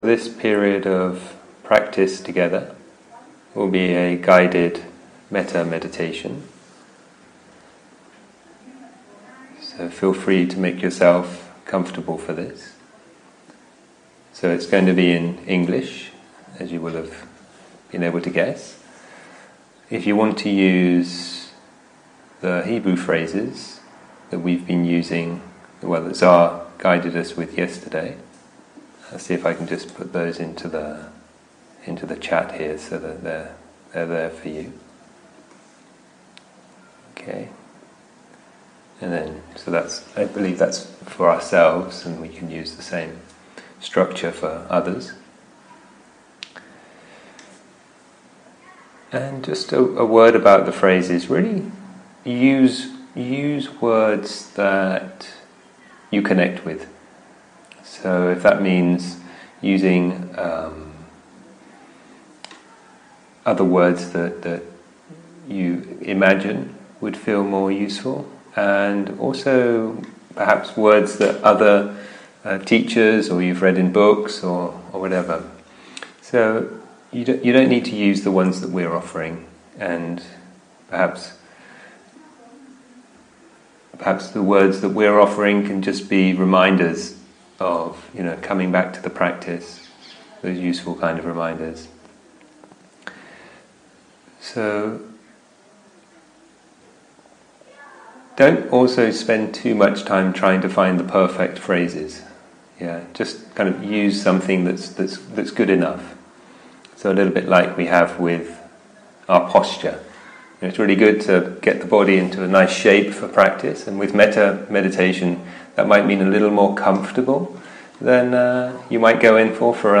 Mettā to Self, Friend, those Suffering, and Whole World - Meditation
אנגלית איכות ההקלטה: איכות גבוהה תגיות